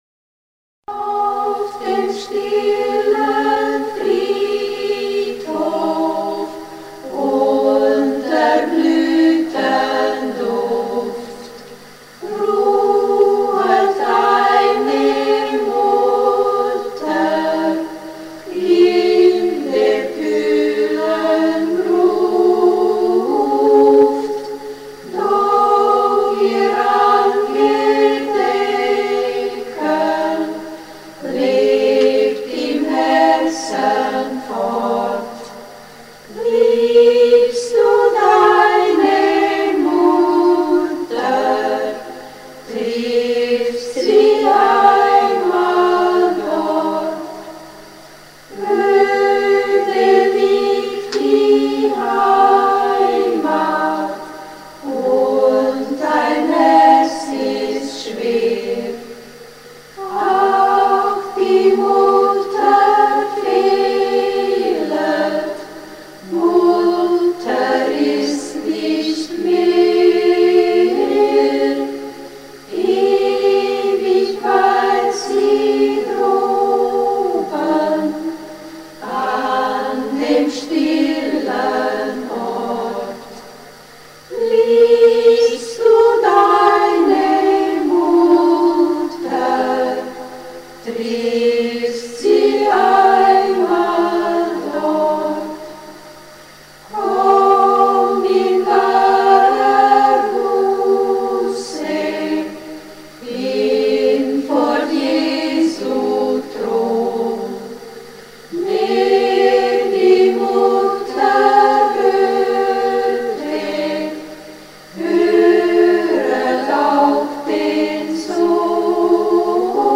Mit den damals in Siebenbürgen vorhandenen Mitteln war eine sehr gute Qualität der Aufnahmen leider nicht möglich.
Frauenchor: "Auf dem stillen Friedhof"